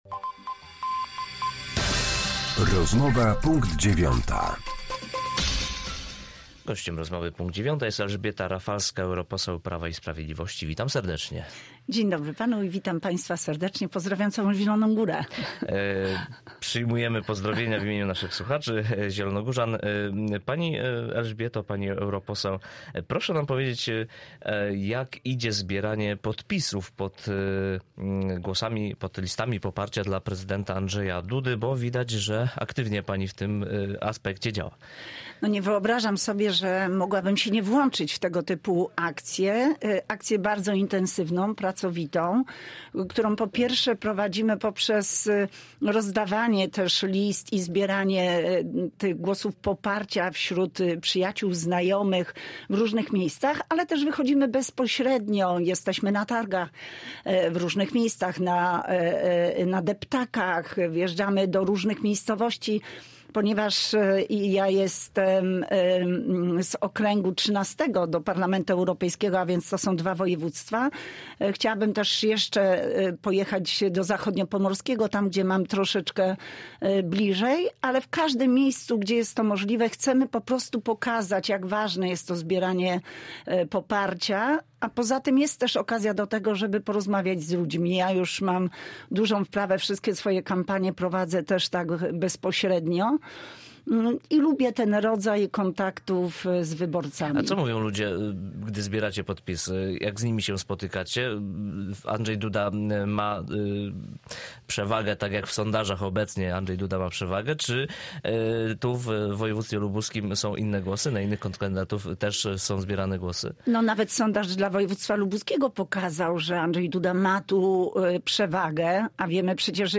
Z europoseł Prawa i Sprawiedliwości rozmawia